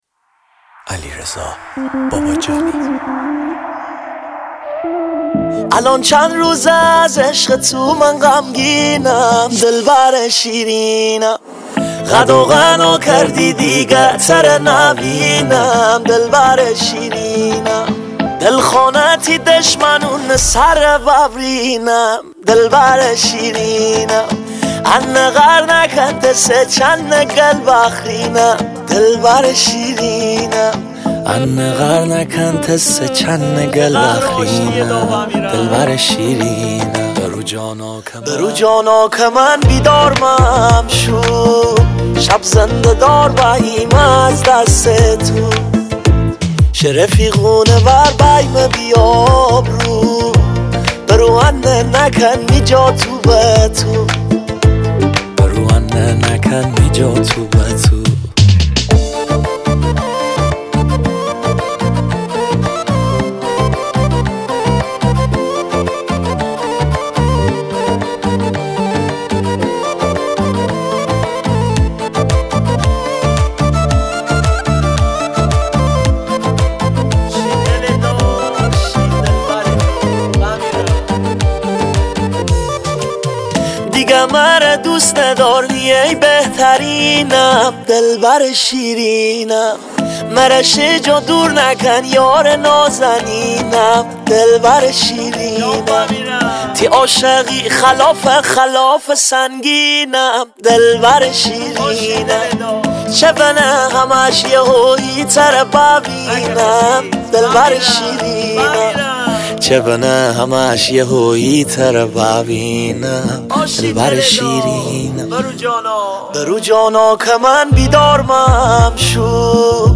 ریتمیک ( تکدست )
سبک ریتمیک مازندرانی